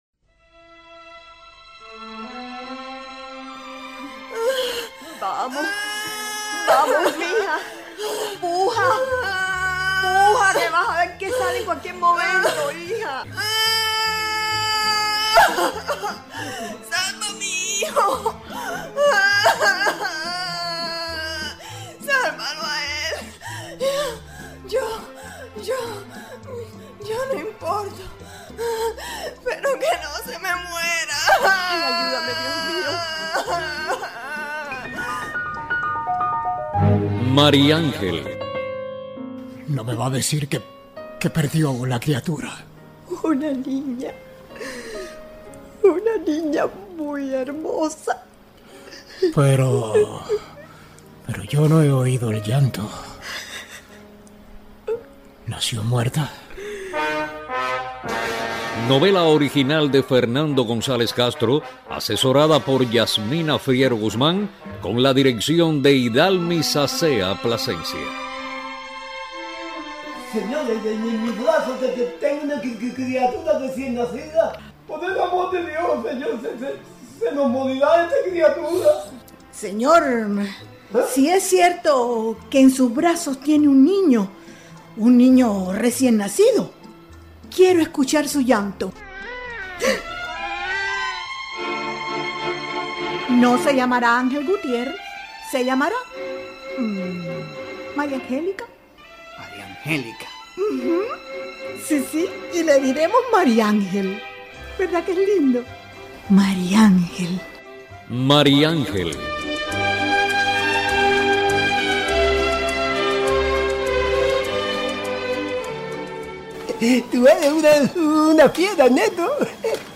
El Sitio Web de Radio Ciudad del Mar propone escuchar algunos de los trabajos que concursan en el Festival Provincial de la Radio en Cienfuegos. En la categoría espacios escenificados